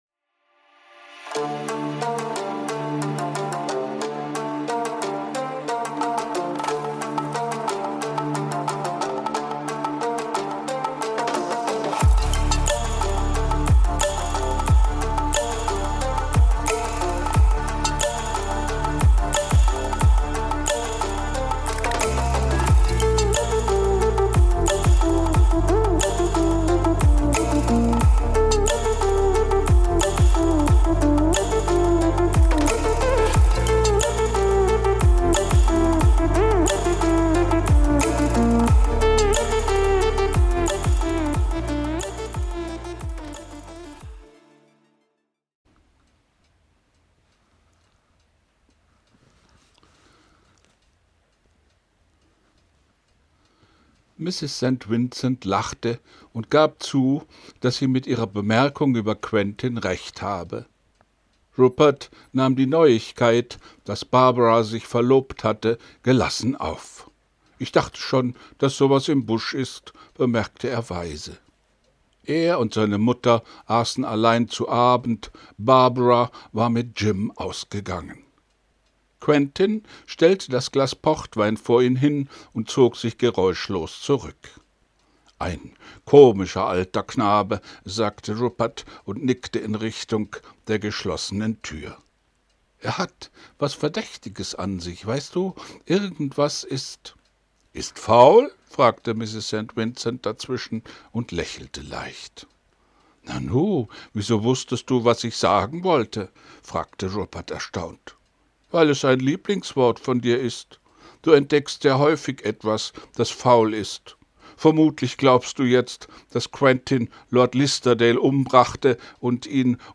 ich lese vor christie etwas ist faul 4